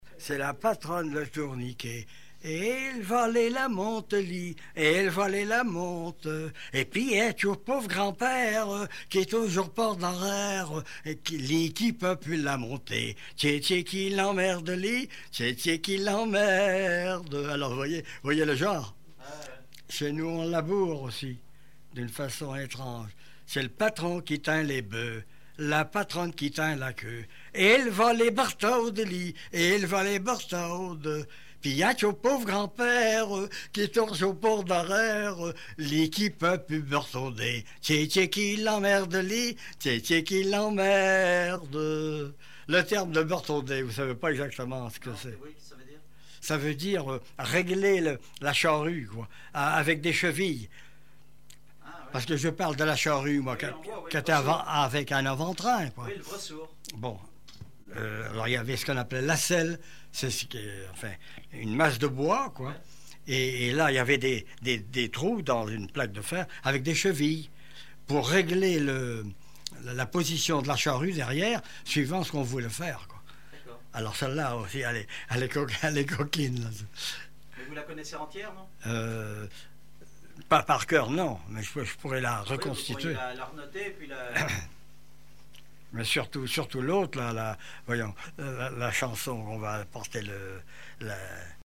Genre énumérative
Chansons et témoignages
Pièce musicale inédite